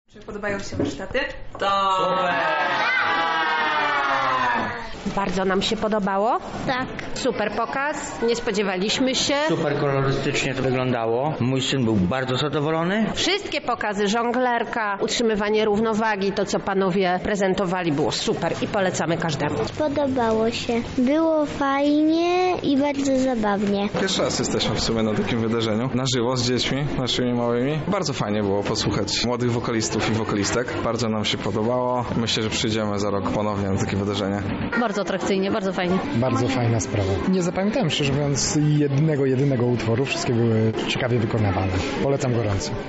Za nami 31. Lubelski Finał Wielkiej Orkiestry Świątecznej Pomocy. W ramach tegorocznej edycji wzięliśmy udział w różnorodnych aktywnościach w Centrum Kultury w Lublinie.
Posłuchajmy co mieli do powiedzenia uczestnicy